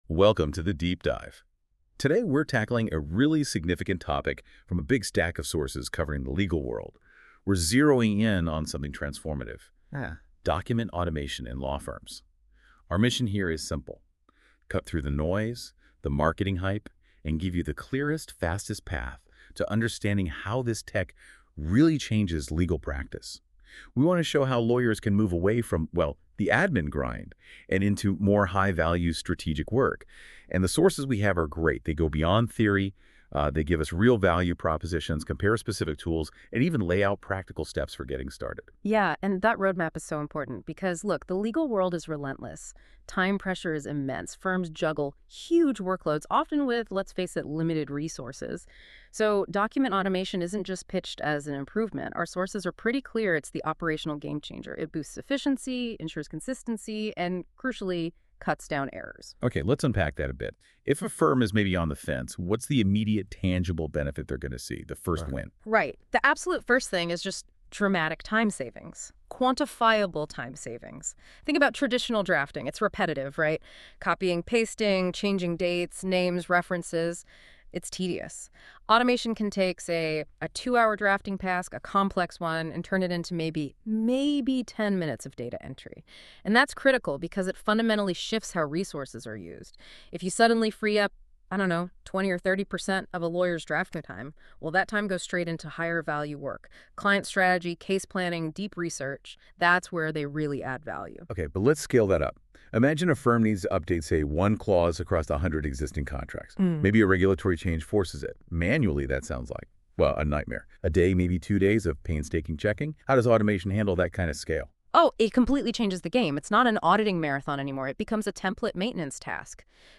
Google NotebookLM If you'd like to hear a Google NotebookLM podcast on this blog, you can do so here .
Google Notebook LM - Why Document Automation is so helpful for law firm efficiency.m4a